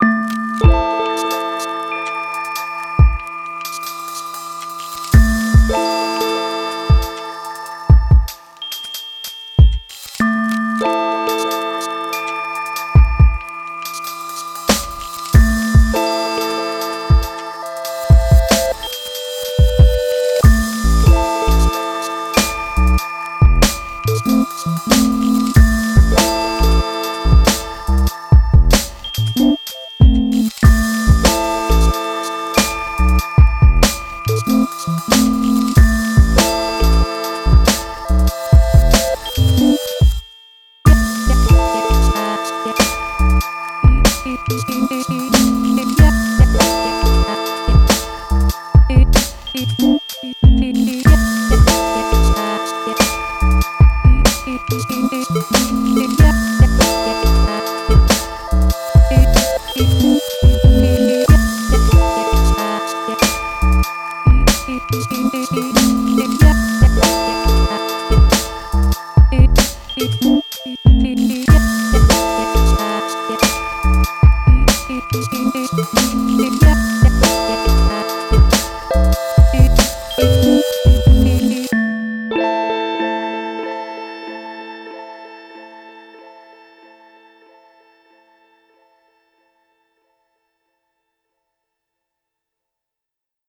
Warm dreamy night time atmosphere over oddball beat.